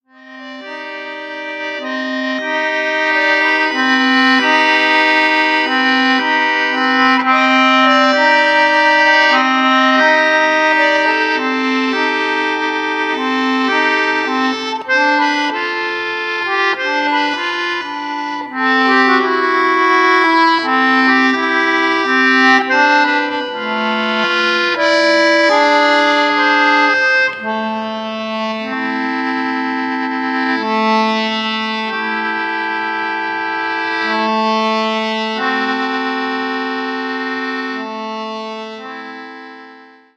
Here’s an extract from one of the instrumental tracks.